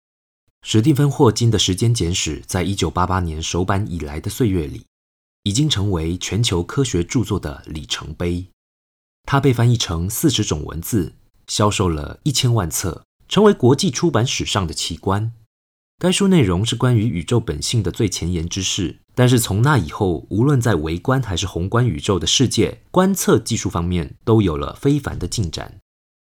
男声Male Voice-公司名
TV19-6 台湾腔男声 YX-工商影片-專業口吻-口說歷史 (純聲音)